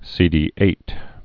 (sēdē-āt)